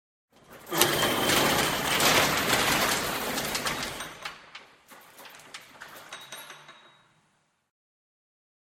blast door open.ogg